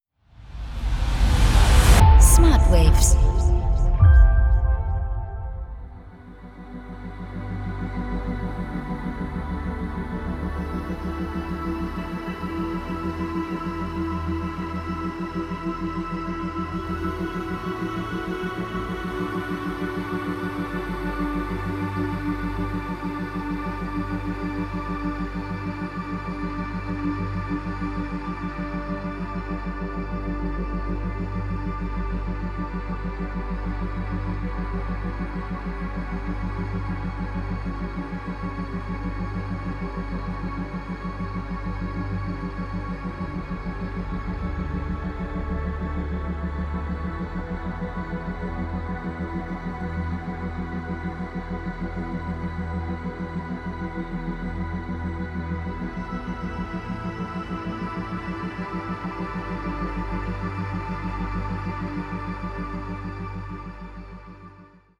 4-8 Hertz Theta Wellen Frequenzen